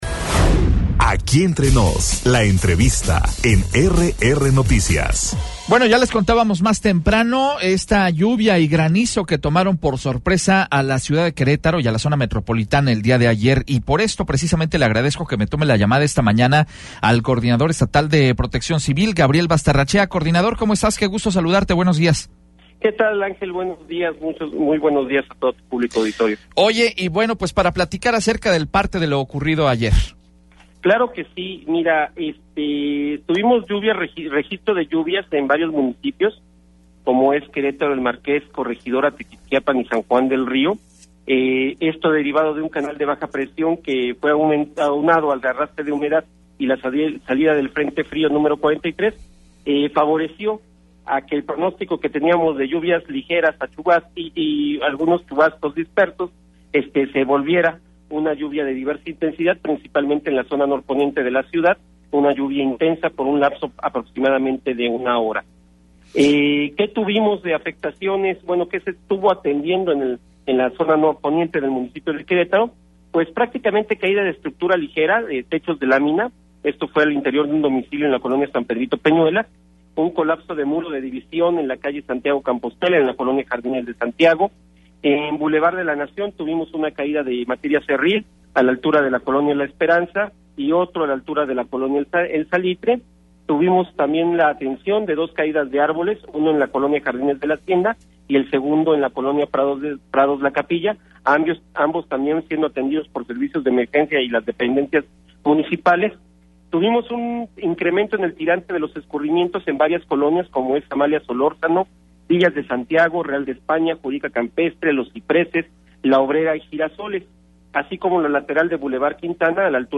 Entrevista con Gabriel Bastarrachea coordinador estatal de PC - RR Noticias